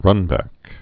(rŭnbăk)